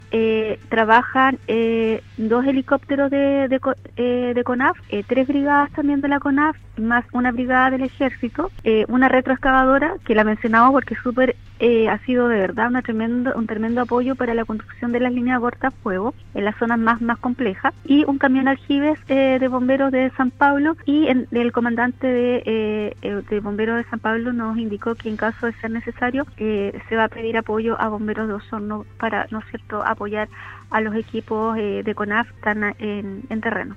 En conversación con Radio Sago, la delegada Claudia Pailalef detalló se levantaron todas las unidades bomberiles que estaban prestando apoyo hasta este miércoles, entre ellos, el puesto de mando levantado por la USAR de Puerto Montt, por lo que ahora toda la coordinación queda en manos del Cuerpo de Bomberos de San Pablo.